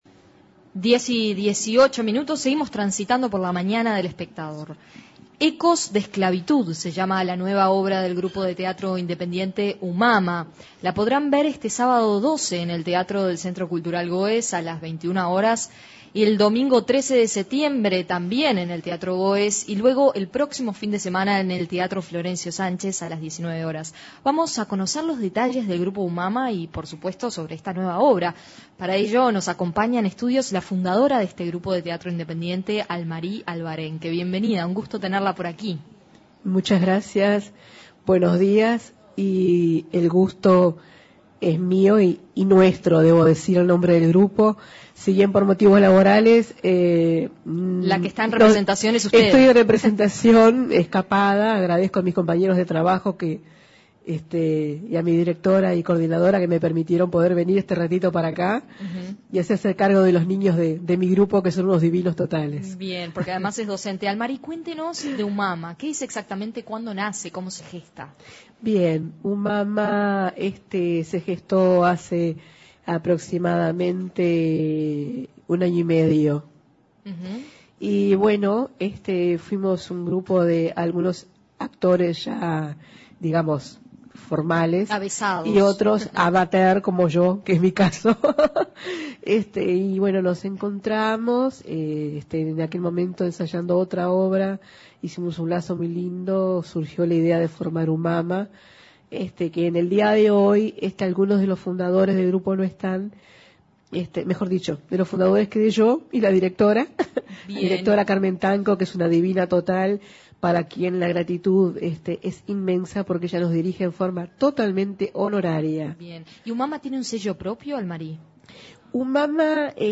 visitó "Una pausa para el café" para contar más detalles sobre el espectáculo.